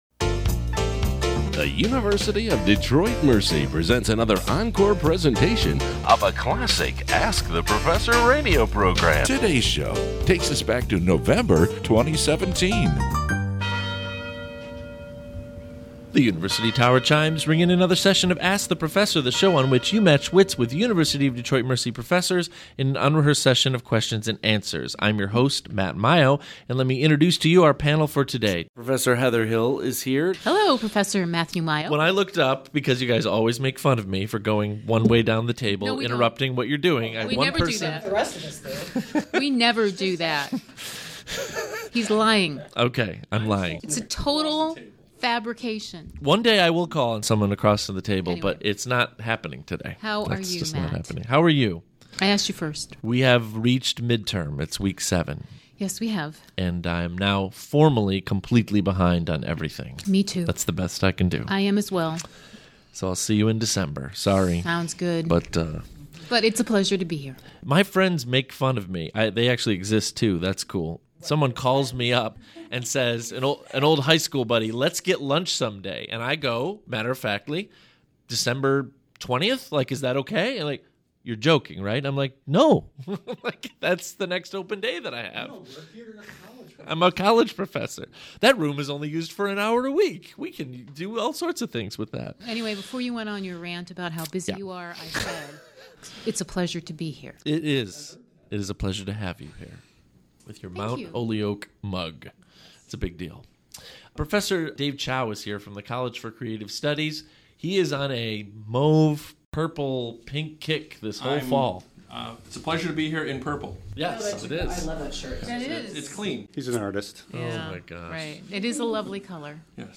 University of Detroit Mercy's broadcast quiz show